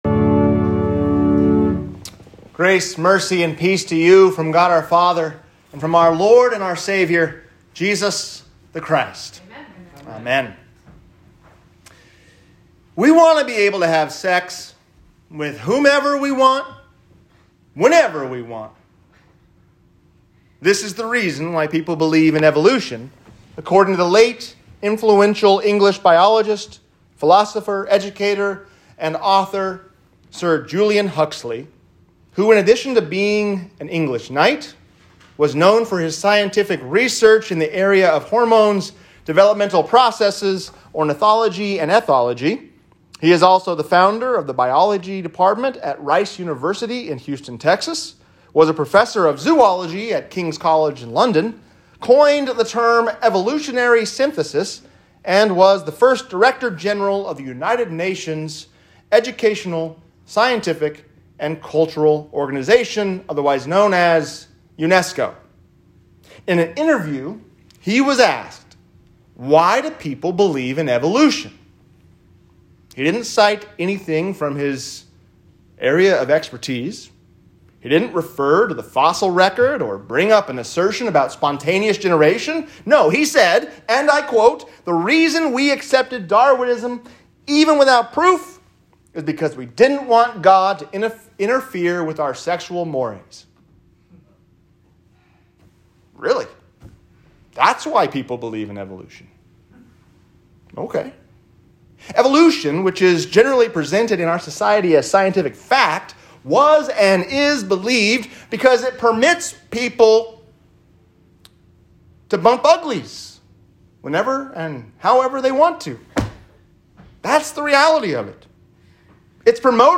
The World Does Not Know Him | Sermon